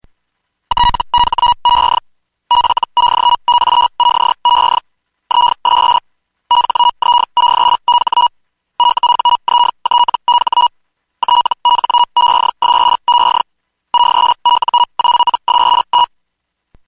dino01.mp3